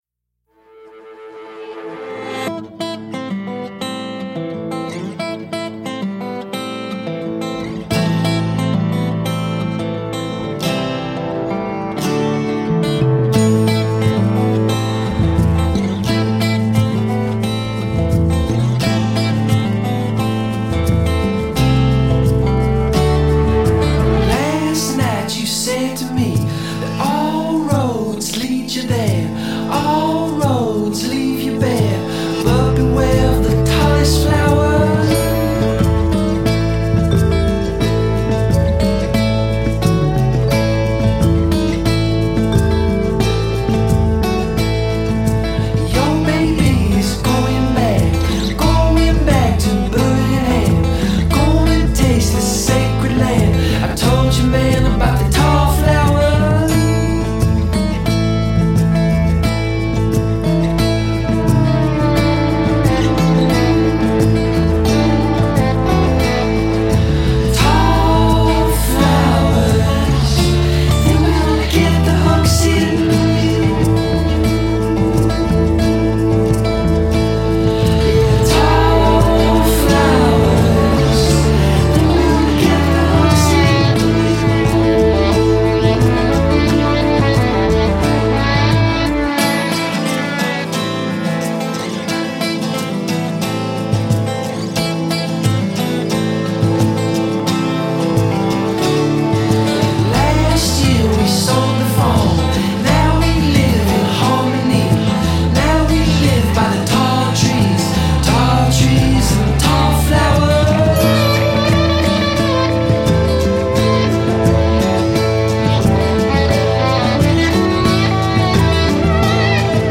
arrestingly vibey music
sepia toned surf psychedelic grooves